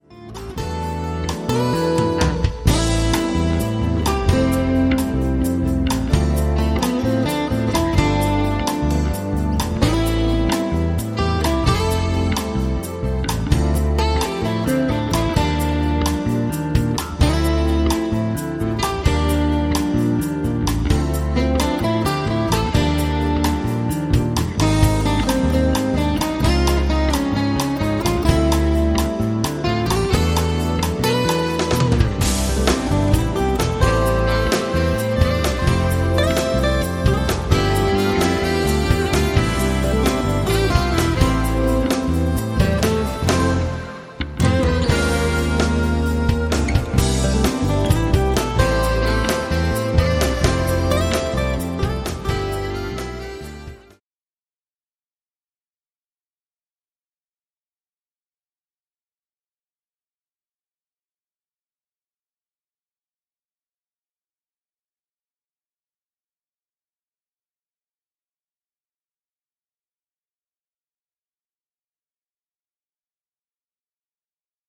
guitar, sax, pan flute and harmonica